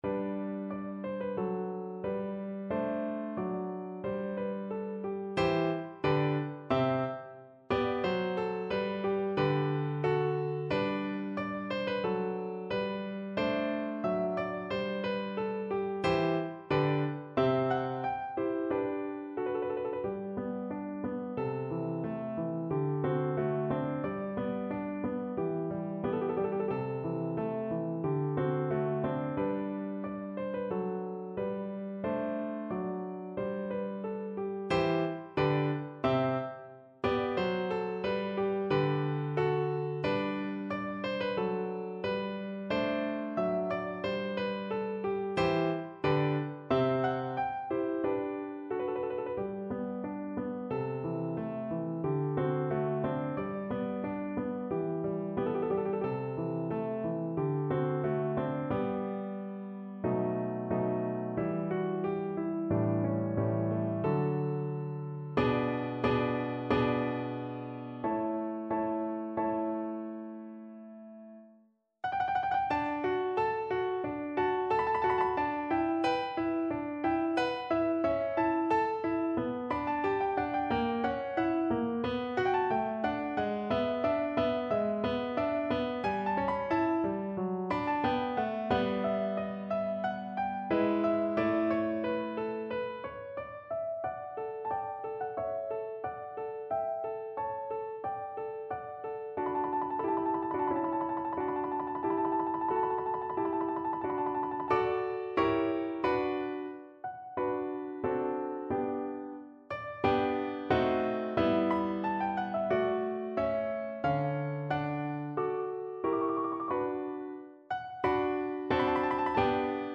No parts available for this pieces as it is for solo piano.
2/4 (View more 2/4 Music)
Piano  (View more Intermediate Piano Music)
Classical (View more Classical Piano Music)